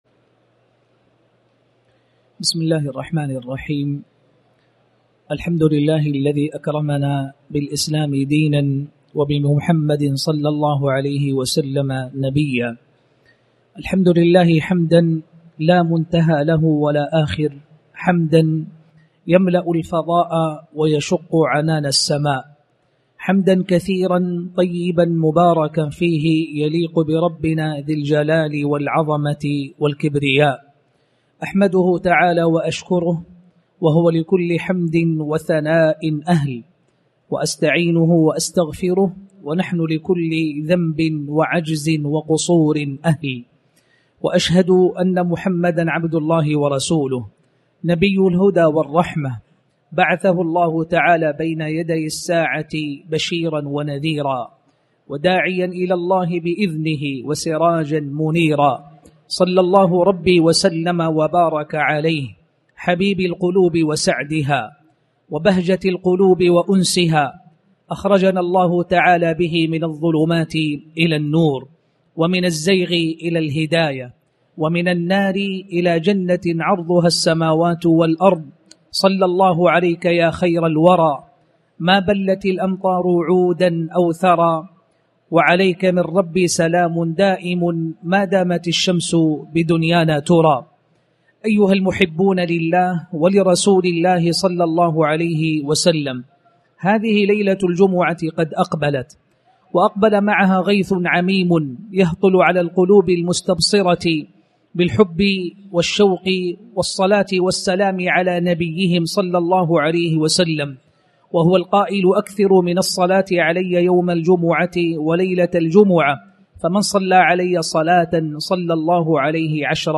تاريخ النشر ٢٩ محرم ١٤٣٩ هـ المكان: المسجد الحرام الشيخ